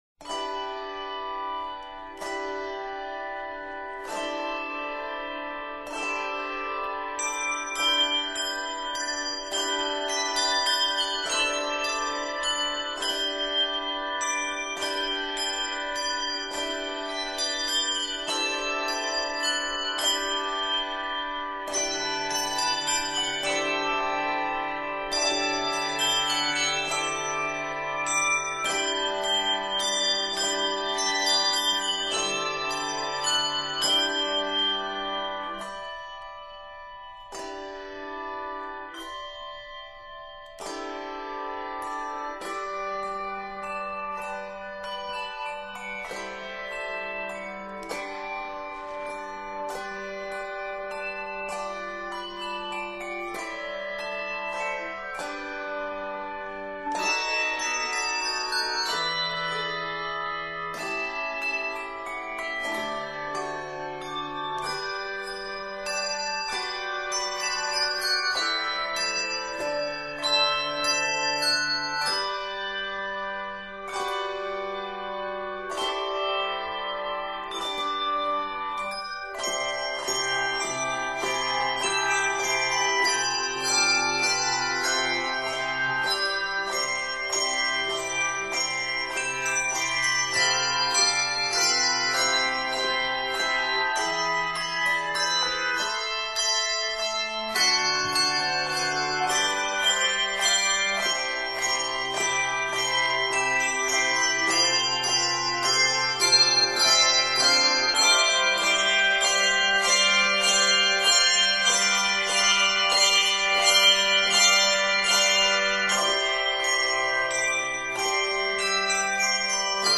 handbells
piano